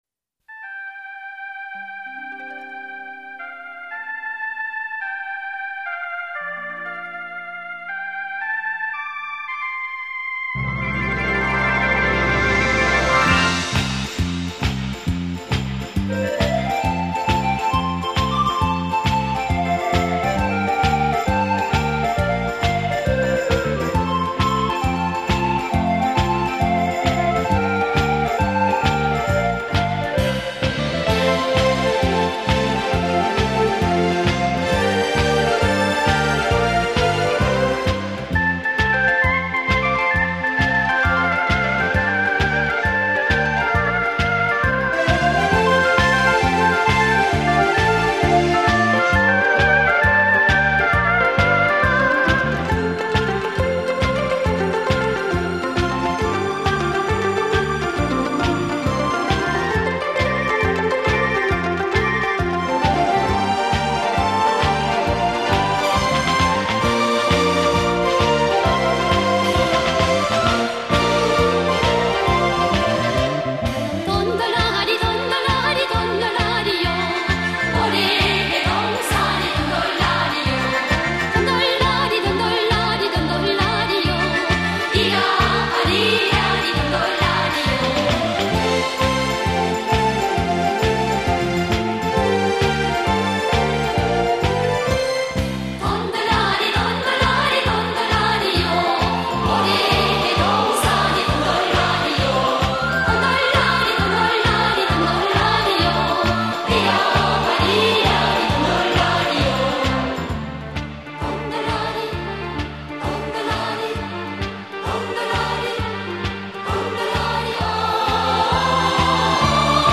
Народные песни